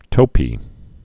(tōpē)